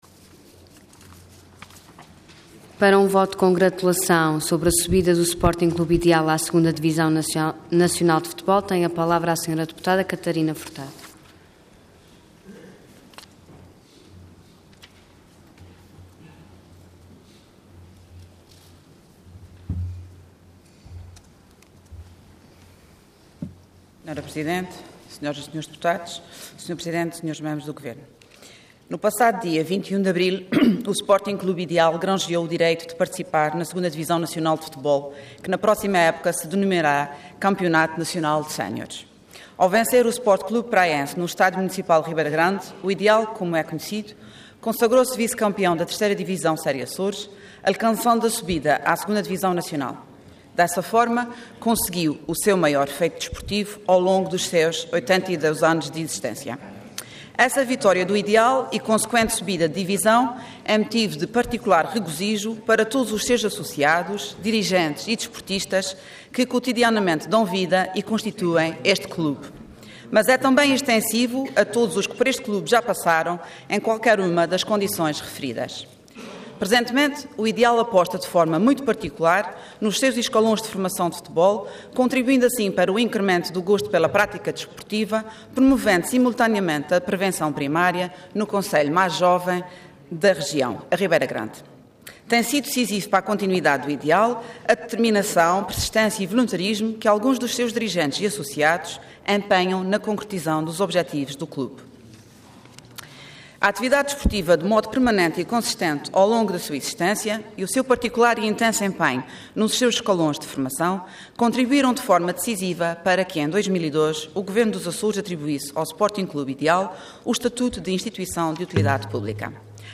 Intervenção Voto de Congratulação Orador Catarina Moniz Furtado Cargo Deputada Entidade PS